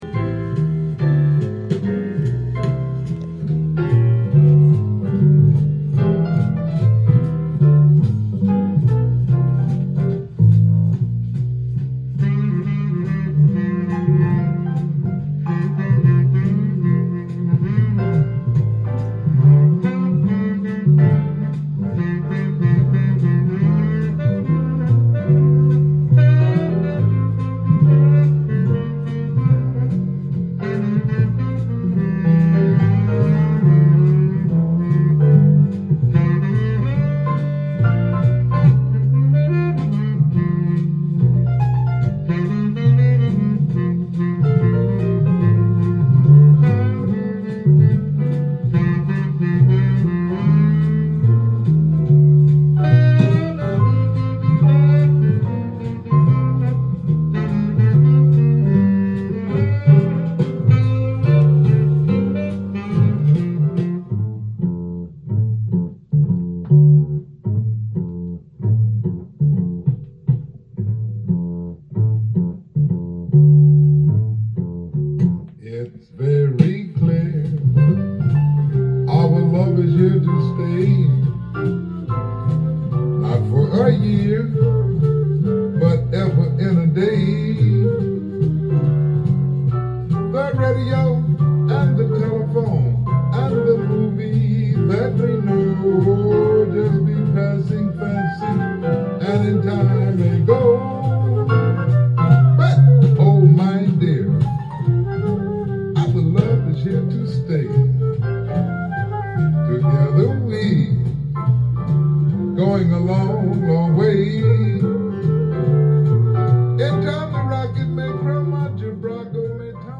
ジャンル：JAZZ-ALL
店頭で録音した音源の為、多少の外部音や音質の悪さはございますが、サンプルとしてご視聴ください。
Jazzサックス奏者